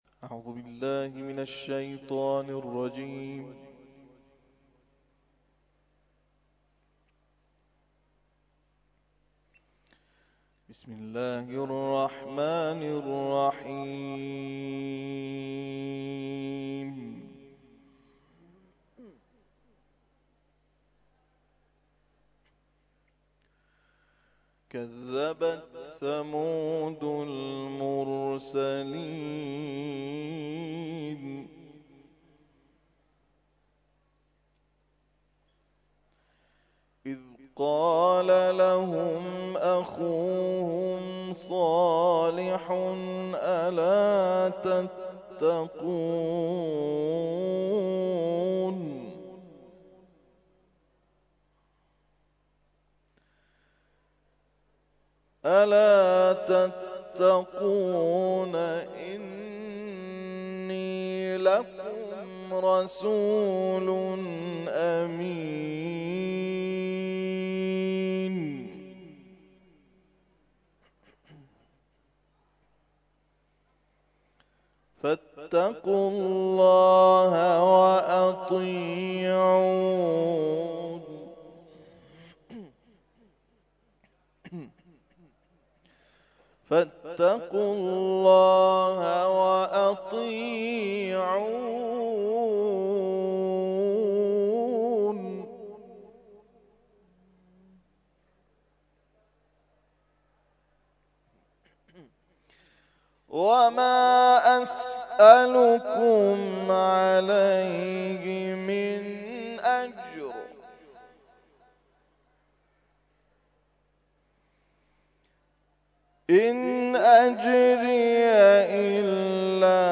تلاوت مغرب
تلاوت ظهر